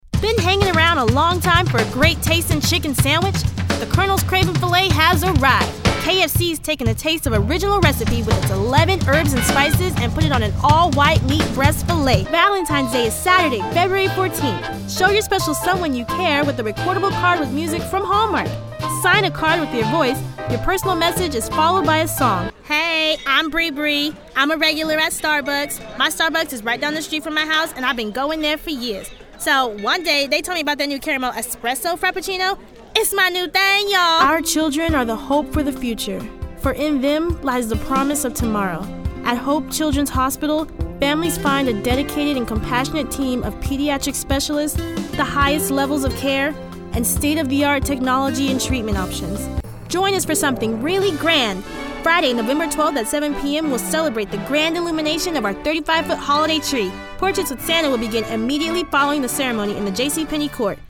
Kein Dialekt
Sprechprobe: Werbung (Muttersprache):